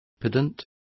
Complete with pronunciation of the translation of pedants.